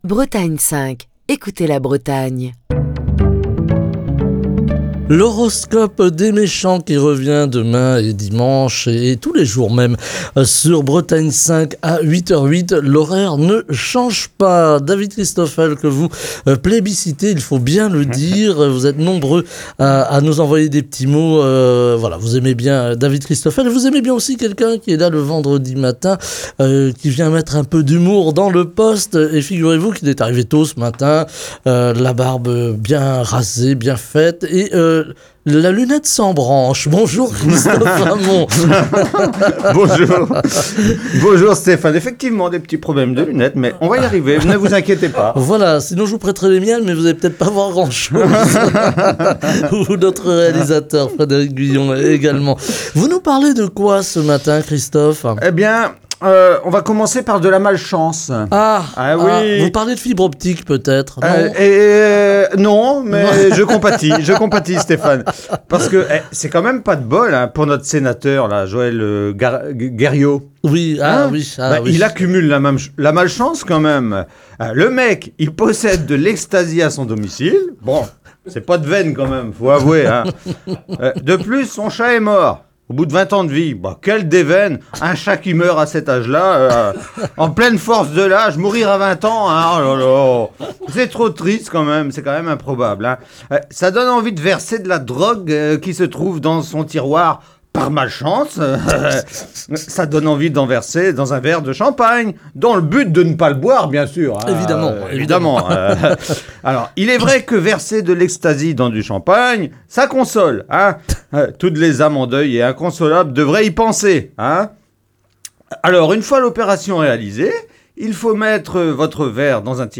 Chronique du 24 novembre 2023.